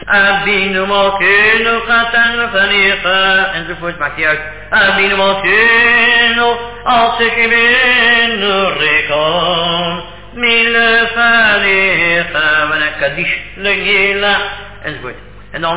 Chazzan